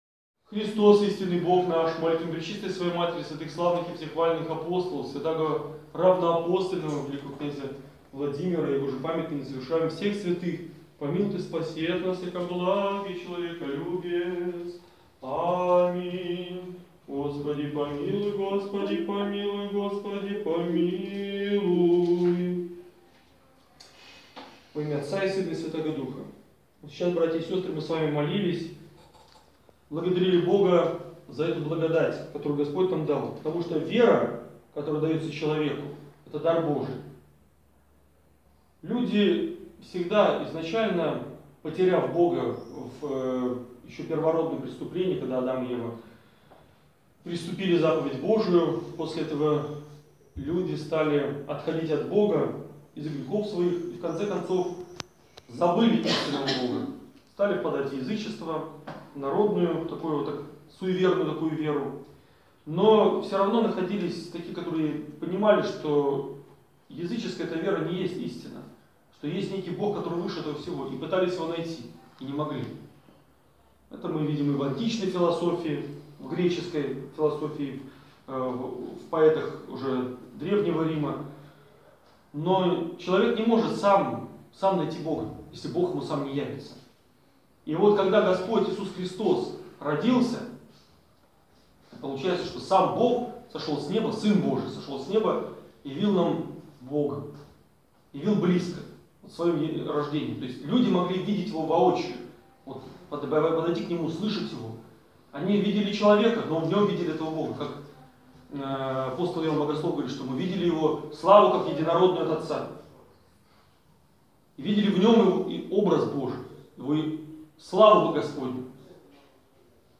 Молебен в доме престарелых в Антавиляй
Запись проповеди прилагается.